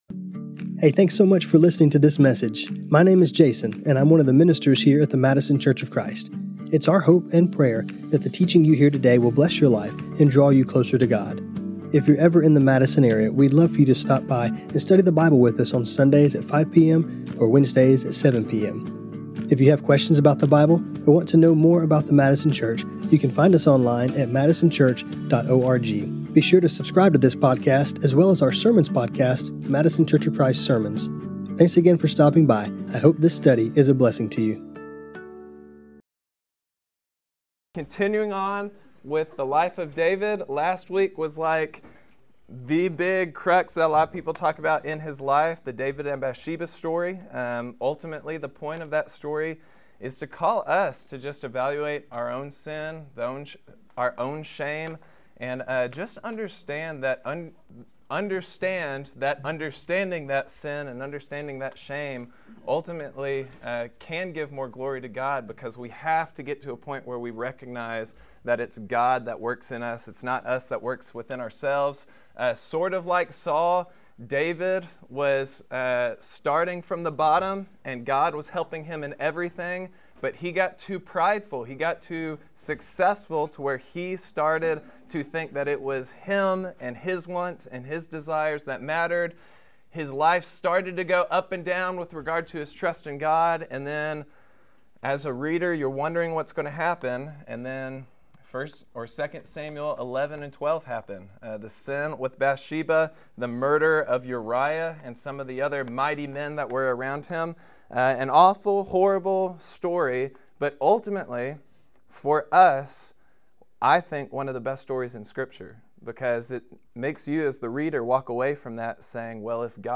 This class was recorded on Jan 14, 2026.